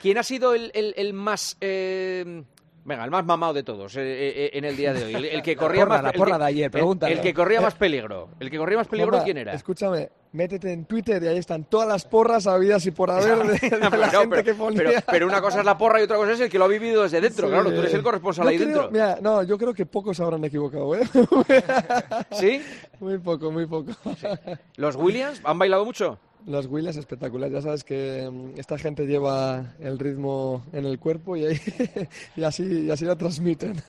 El jugador campeón de la Copa del Rey charló con Juanma Castaño en El Partidazo de COPE desde la fiesta del equipo tras sacar la Gabarra a la ría de Bilbao 40 años después